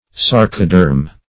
Search Result for " sarcoderm" : The Collaborative International Dictionary of English v.0.48: Sarcoderm \Sar"co*derm\, sarcoderma \sar`co*der"ma\, n. [NL. sarcoderma.
sarcoderm.mp3